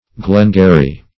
Glengarry \Glen*gar"ry\, n., or Glengarry bonnet \Glen*gar"ry